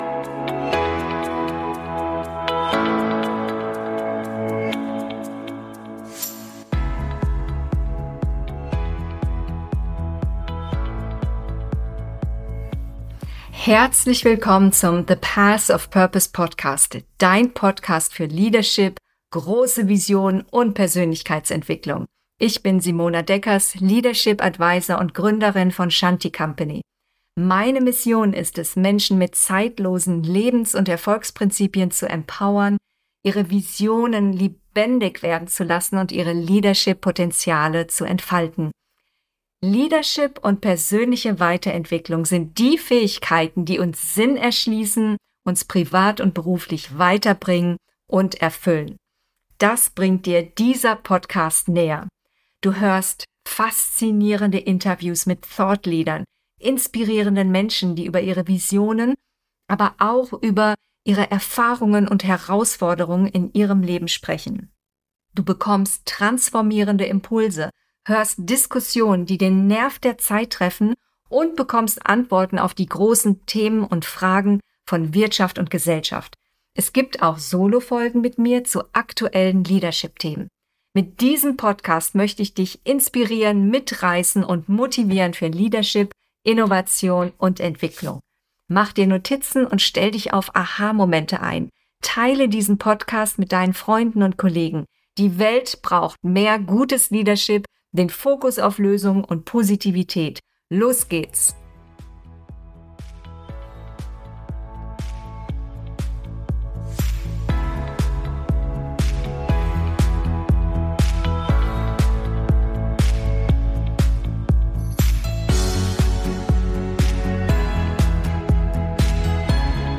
Vom Anwalt zum Medien-CEO: Ein ungewöhnlicher Weg - Interview